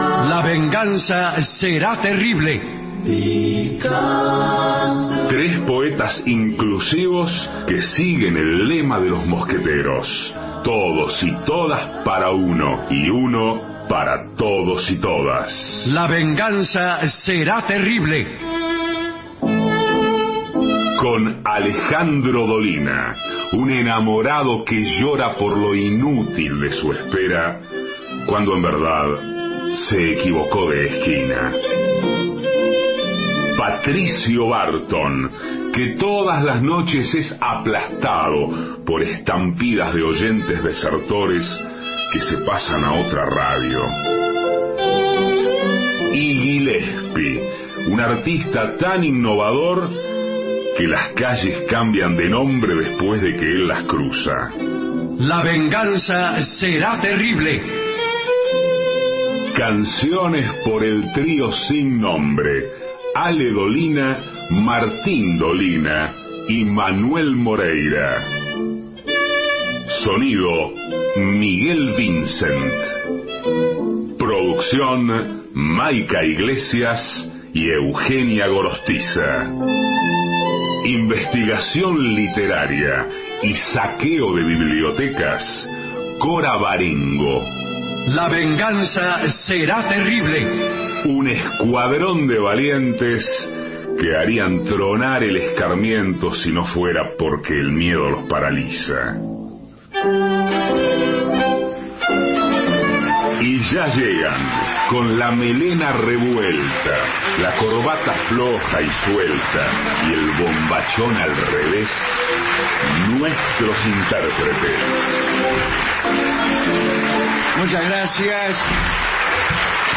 Chacarerean Teatre, Palermo Alejandro Dolina, Patricio Barton, Gillespi Introducción • Entrada[0:02:14]( play 0:02:14) Segmento Inicial • Mi novia me dejó y quiero que se arrepienta[0:05:38](